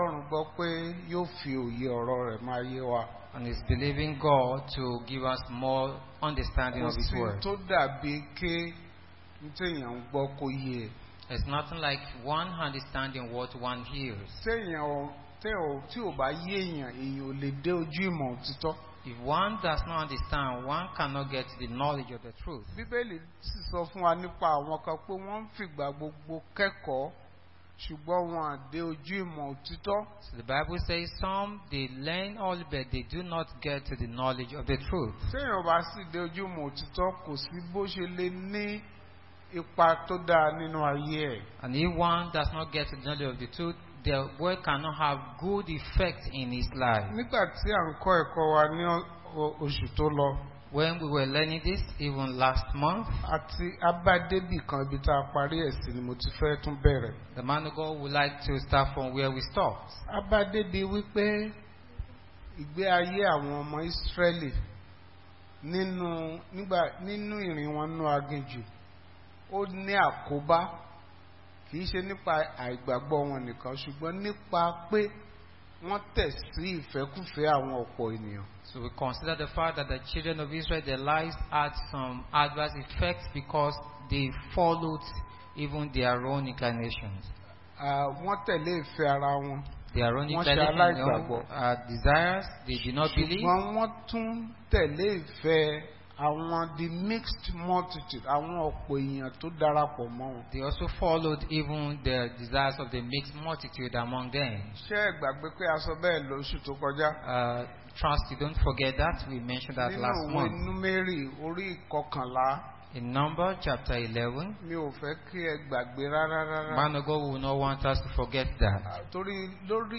Bible Class Passage: Numbers 11:4, Matthew 7:21-23, John 12: 3-6, Romans 12:1-2, James 4:4